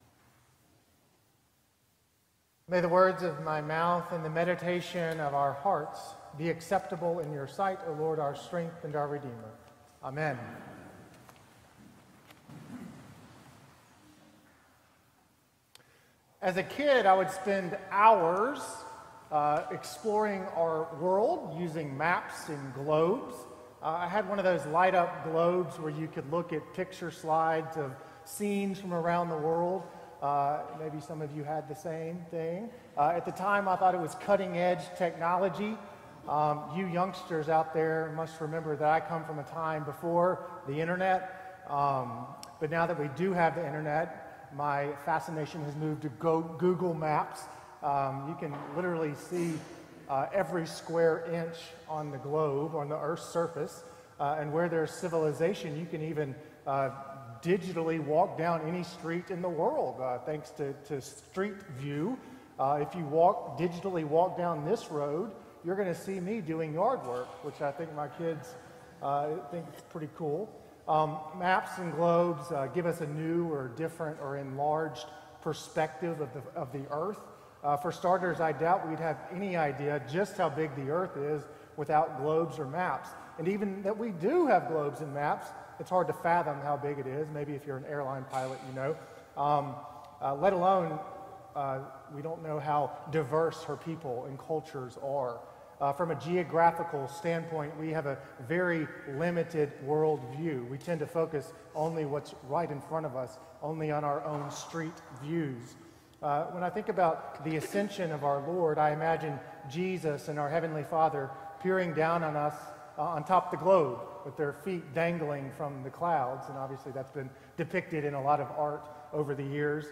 Sermon - March 16th, 2025 - Second Sunday in Lent